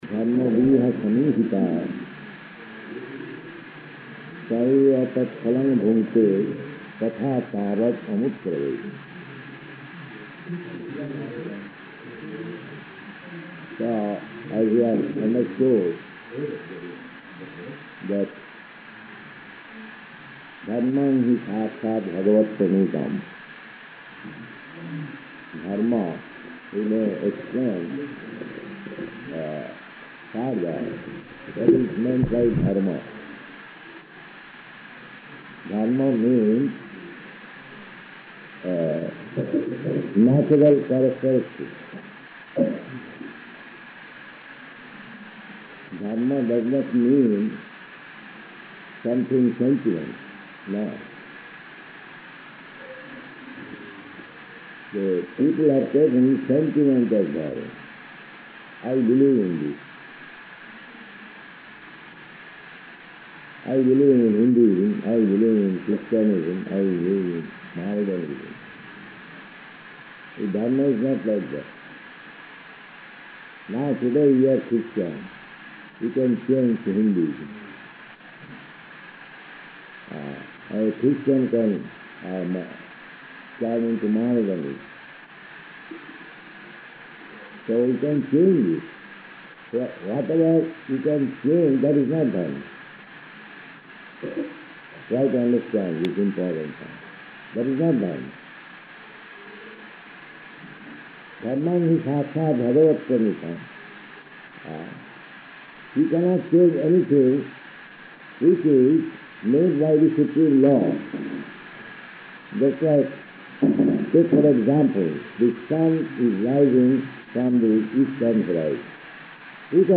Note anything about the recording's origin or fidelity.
Location: Surat